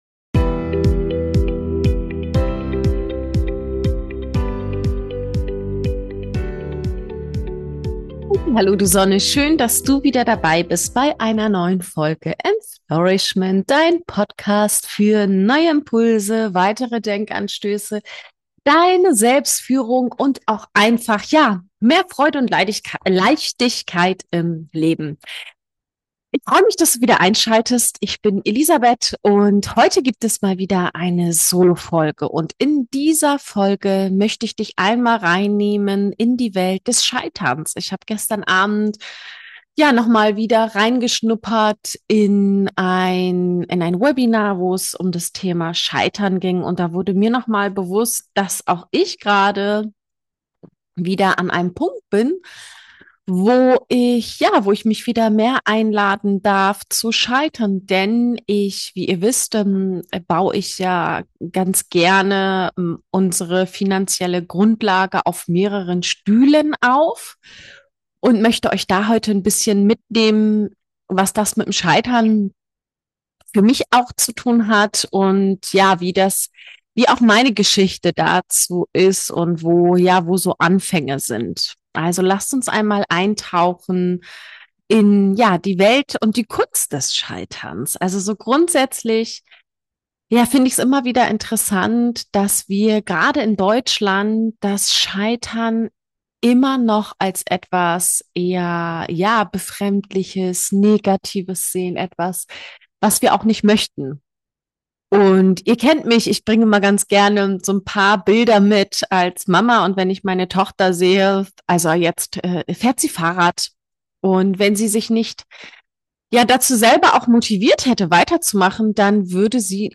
In dieser Solo-Folge nehme ich dich mit in meine persönliche Geschichte rund ums Scheitern – beruflich, privat, an der Börse, in alten Träumen und neuen Projekten.